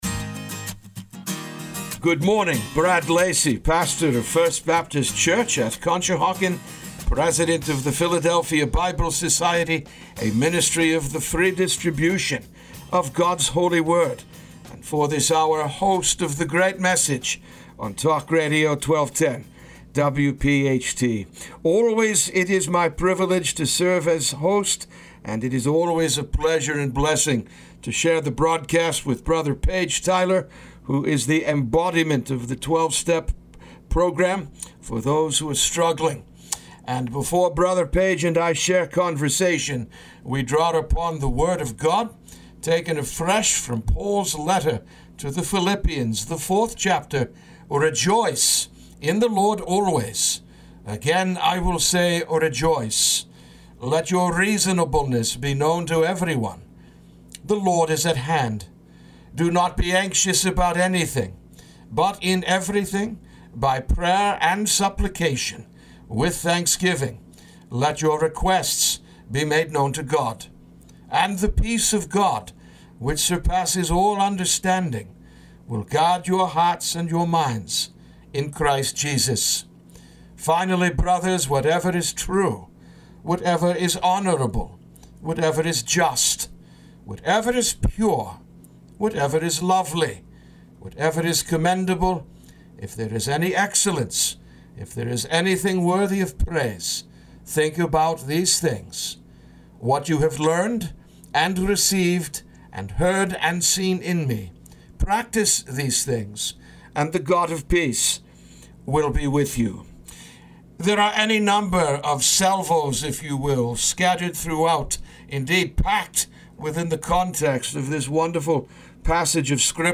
The Great Message A Conversation